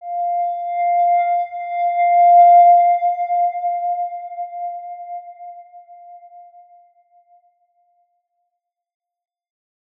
X_Windwistle-F4-mf.wav